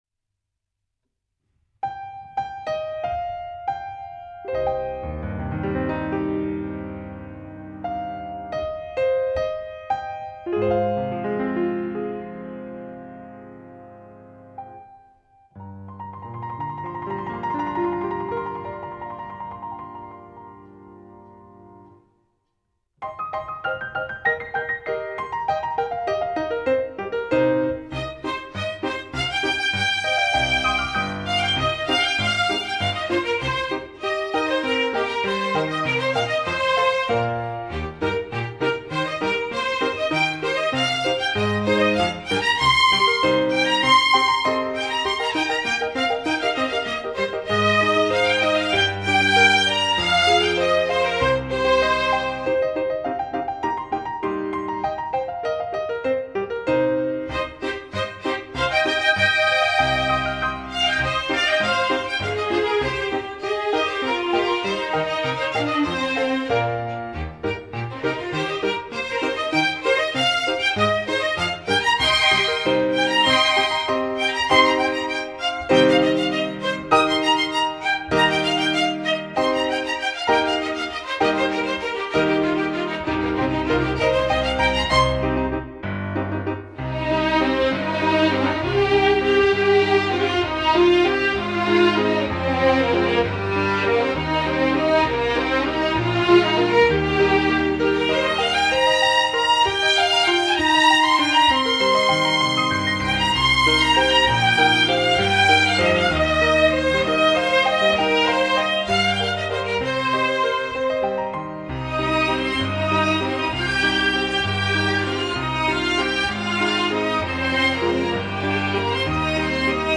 这三张提琴合奏曲，是本人比较喜欢的，既有提琴合奏的大气，又有单独演奏的细腻。
2·纯音乐.-.